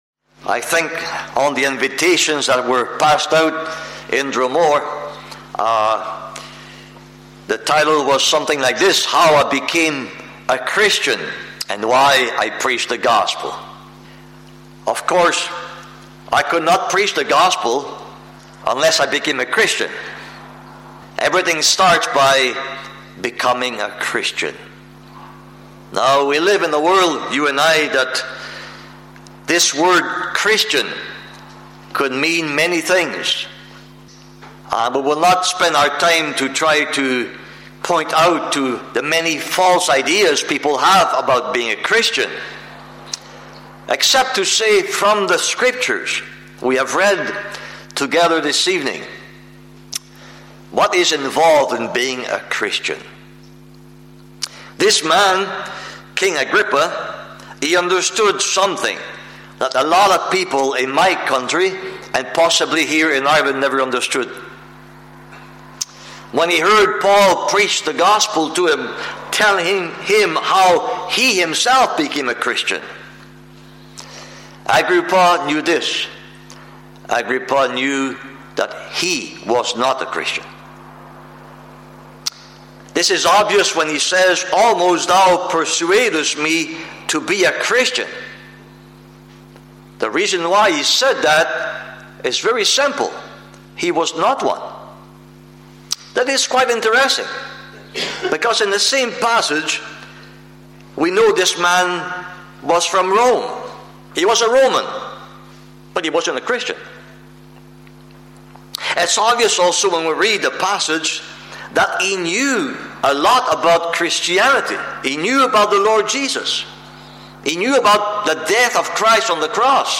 (Recorded in Dromore Gospel Hall, Northern Ireland, 4th June 2025)